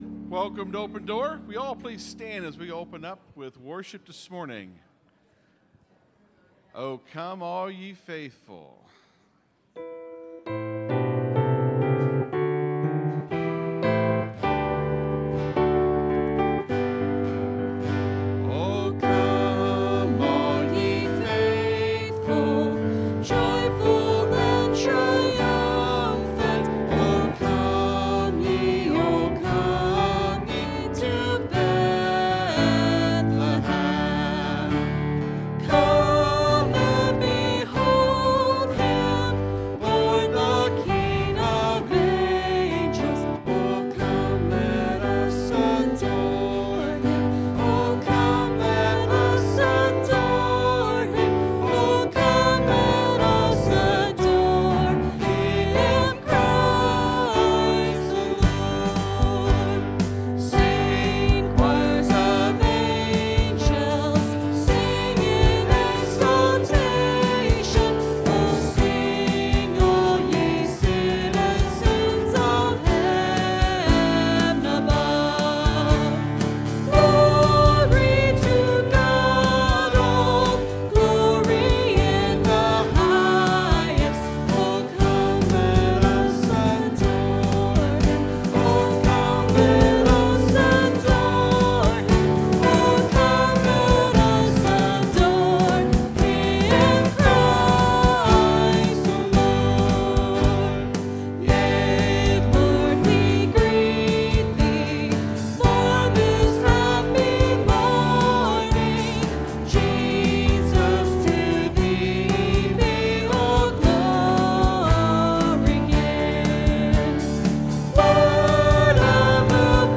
(Sermon starts at 28:30 in the recording).